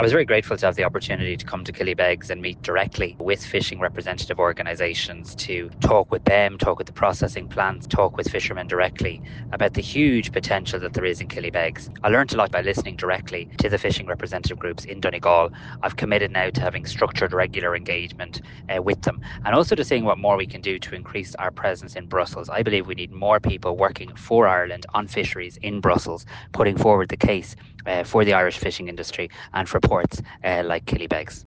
Mr Harris, who holds the international trade brief as part of his ministerial brief, says he learned a lot from his visit……….